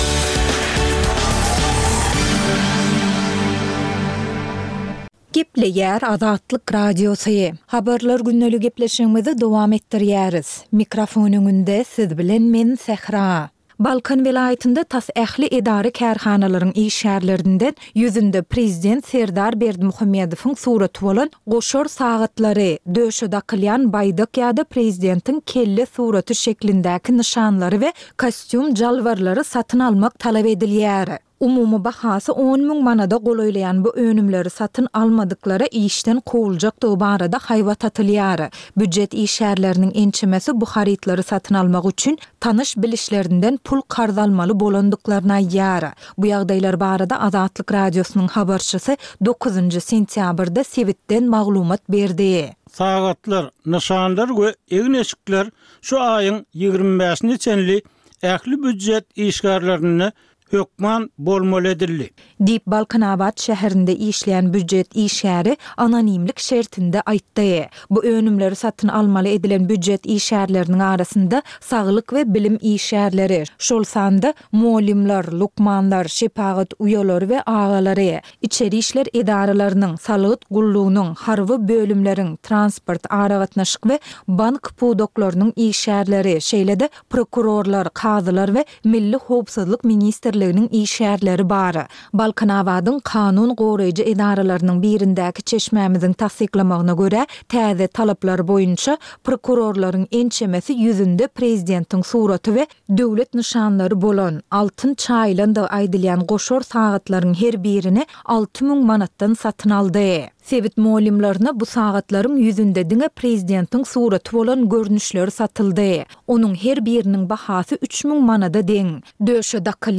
Türkmenistandaky we halkara arenasyndaky soňky möhüm wakalar we meseleler barada ýörite informasion-habarlar programma. Bu programmada soňky möhüm wakalar we meseleler barada giňişleýin maglumatlar berilýär.